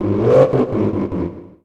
Cri de Gouroutan dans Pokémon Soleil et Lune.